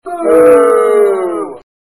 drop.mp3